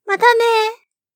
Girl say "See you" (Japanese voice)
Voice
It is a voice material that a high school girl greets "See you again" when she breaks up while retur...